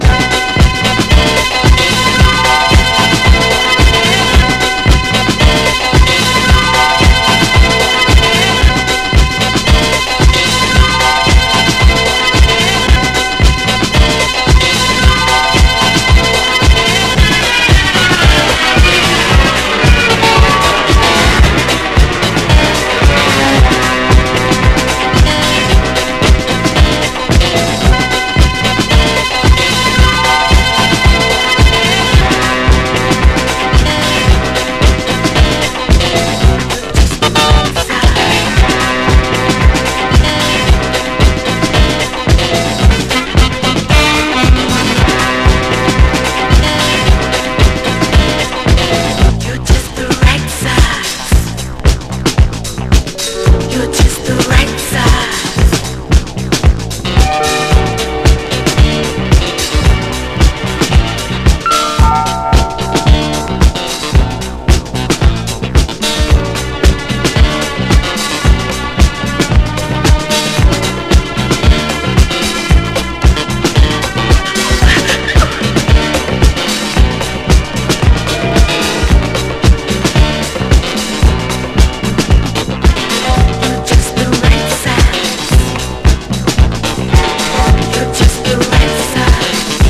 SOUL / RARE GROOVE/FUNK / MEGA MIX / BREAKBEATS
ドラム・ブレイクらでガシガシと繋がれていく力技感強めのUK ONLYメガミックス！